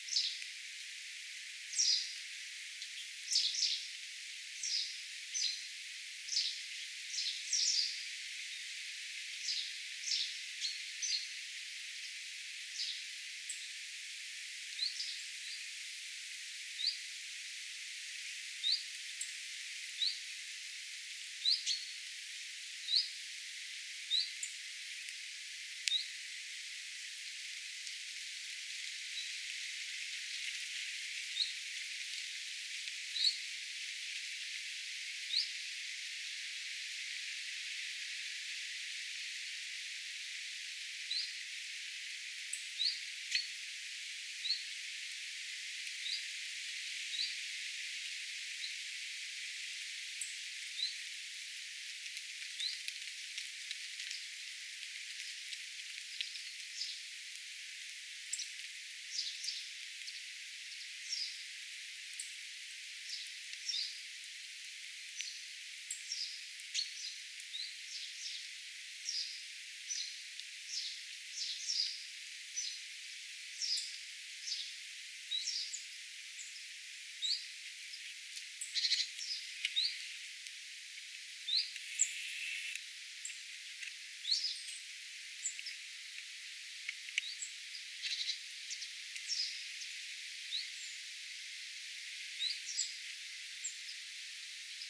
Mutta ääni hieman poikeaa ehkä bizt-äänestä
bizt_tai_vist-tiltaltti_aantelee.mp3